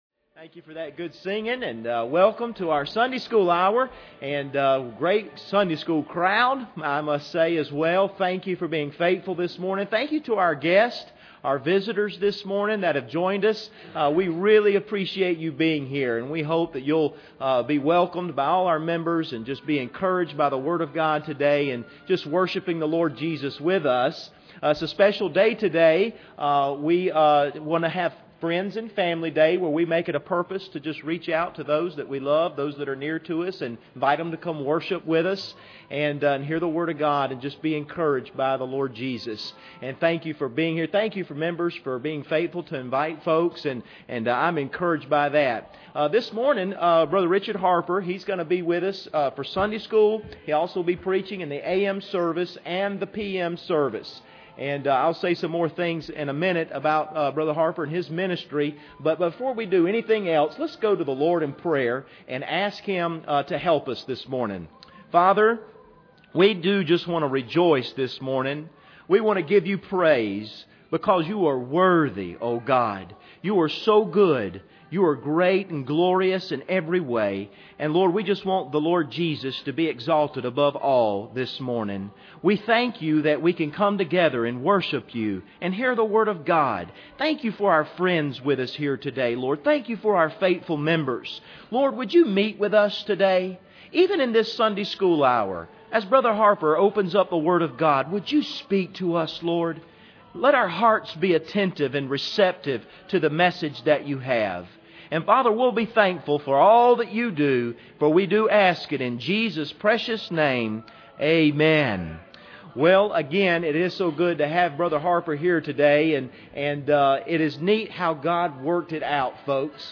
Service Type: Sunday School Hour